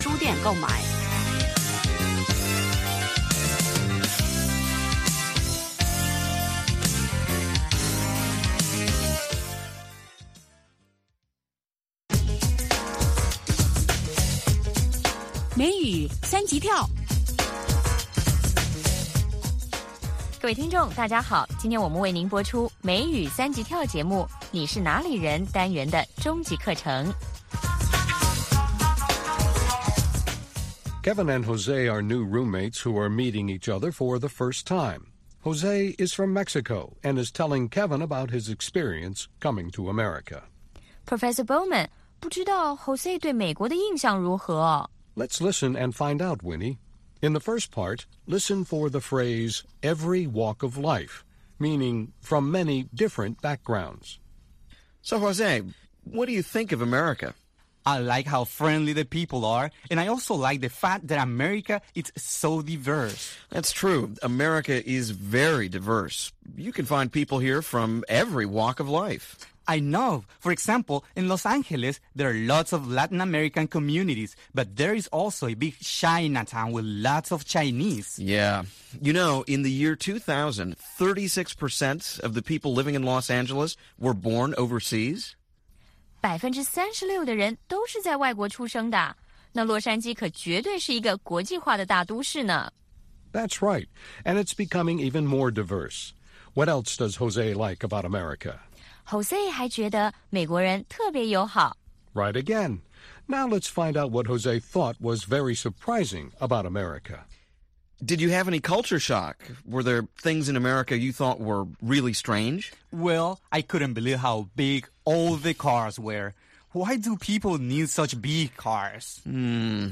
北京时间下午5-6点广播节目。广播内容包括美语训练班(学个词， 美国习惯用语，美语怎么说，英语三级跳， 礼节美语以及体育美语)，以及《时事大家谈》(重播)